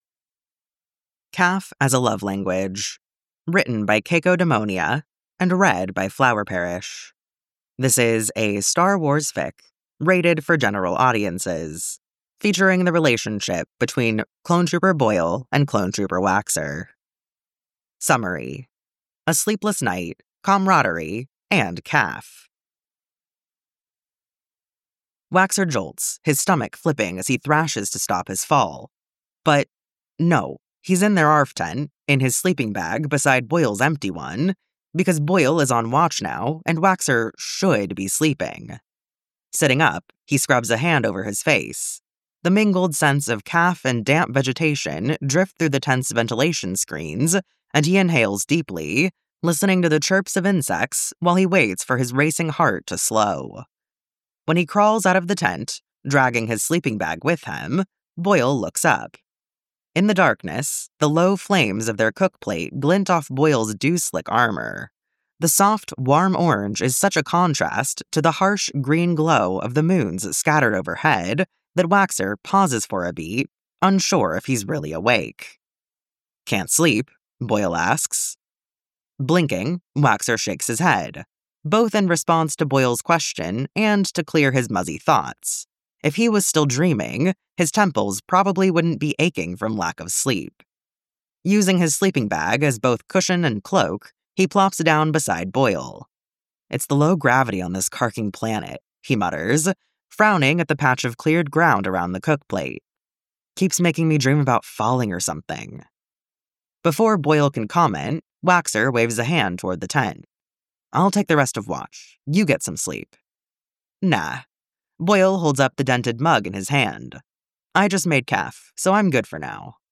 [Podfic] Caf as a love language